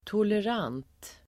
Uttal: [toler'an:t (el. -'ang:t)]